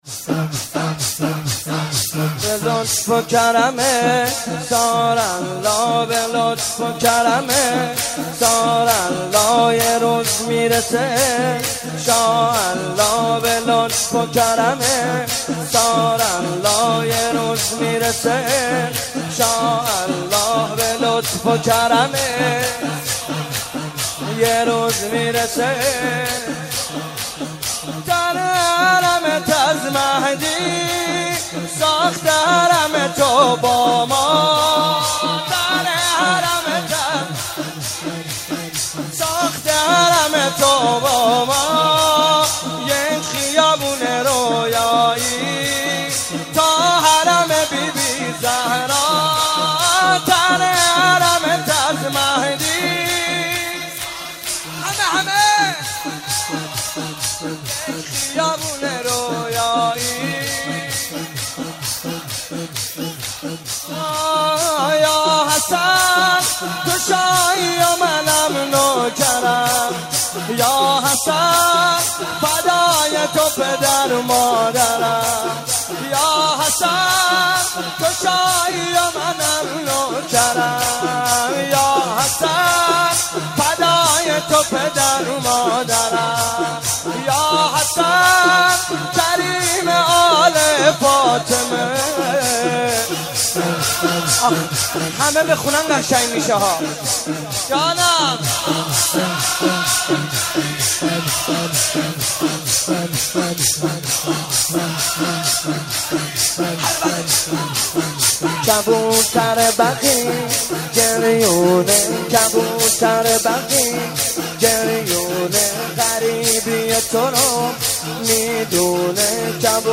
مناسبت : شهادت امام حسن مجتبی علیه‌السلام
قالب : شور